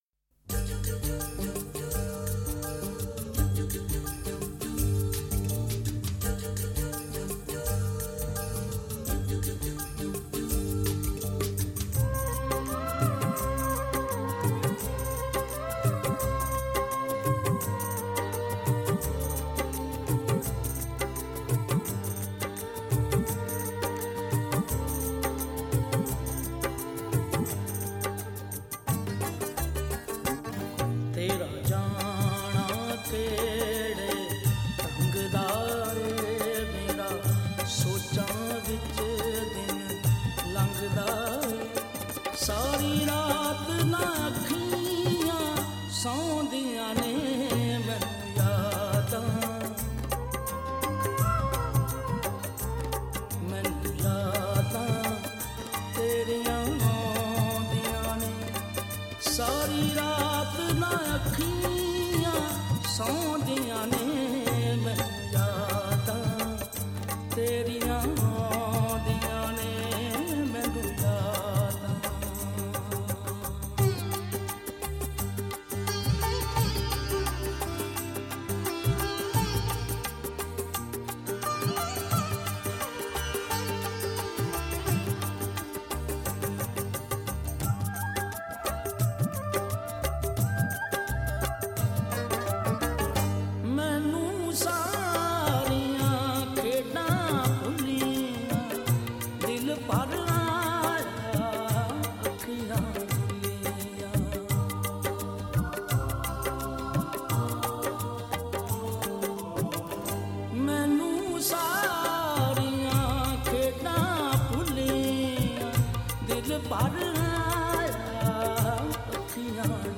powerful and magical voice